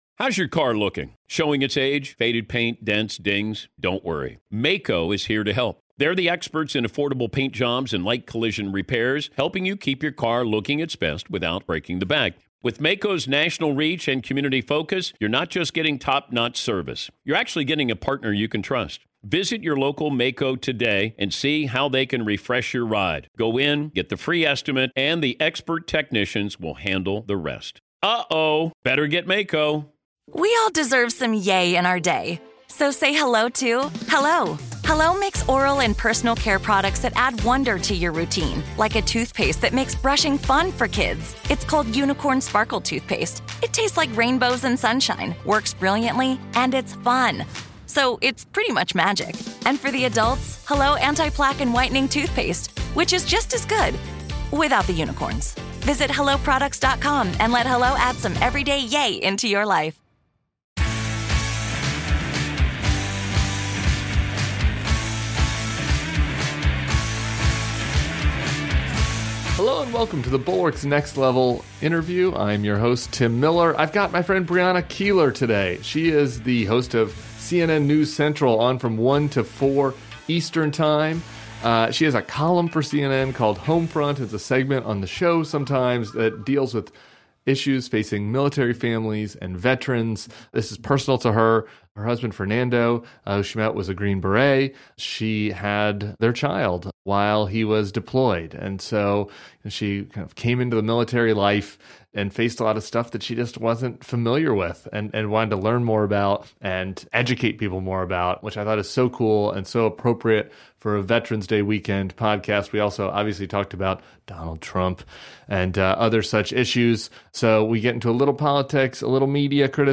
interviews CNN's Brianna Keilar